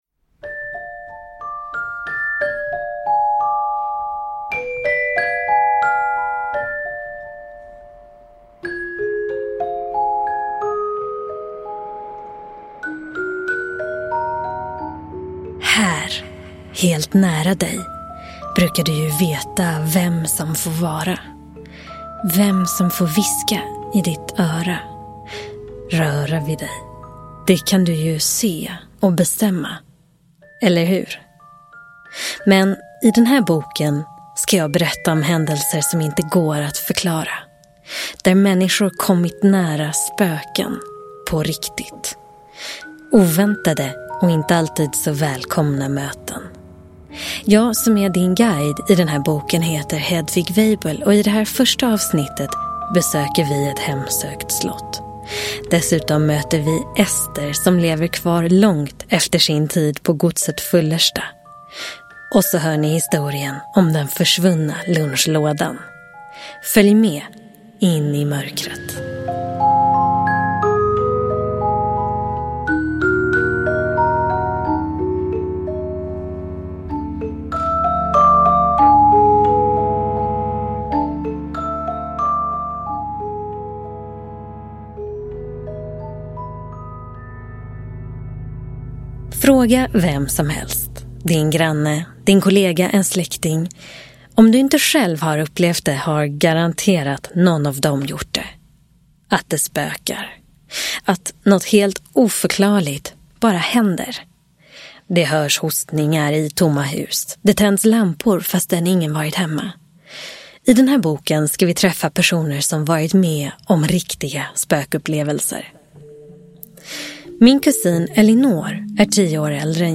Spökhistorier på riktigt. Del 1 – Ljudbok – Laddas ner